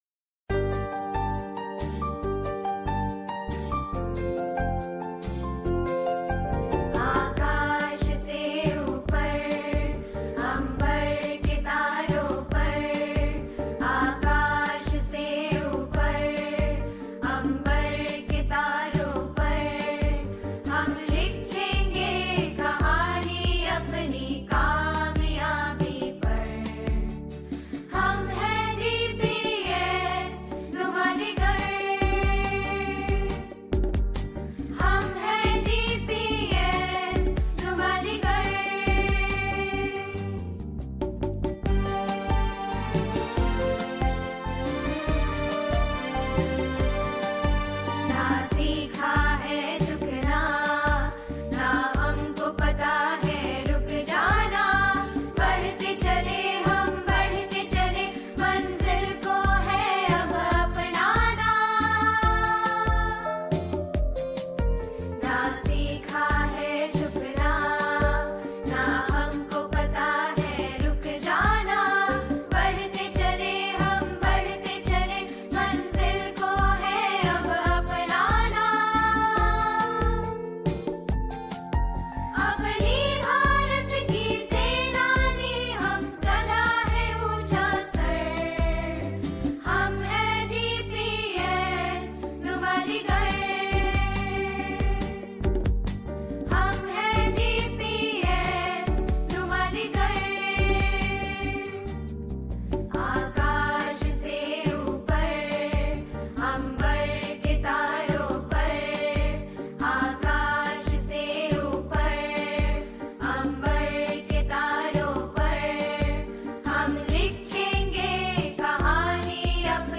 school_anthem.mp3